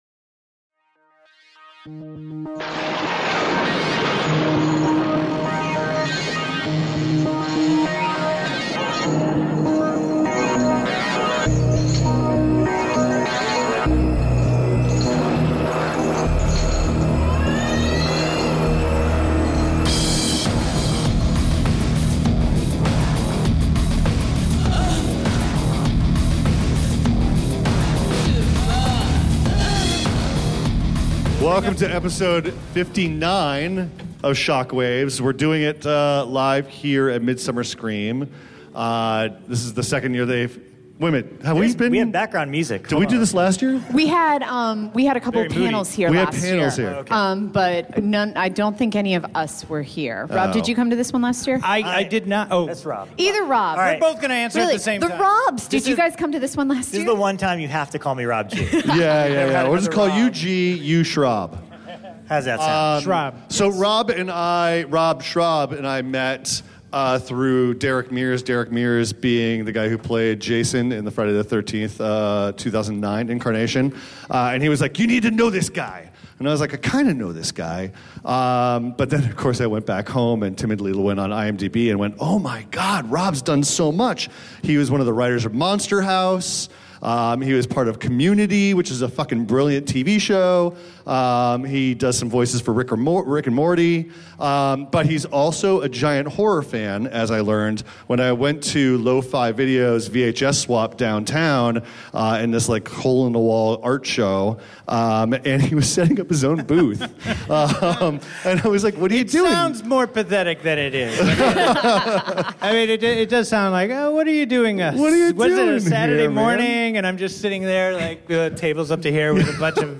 For episode 59, Shock Waves is live in front of a convention audience in Long Beach, CA for MidSummer Scream 2017!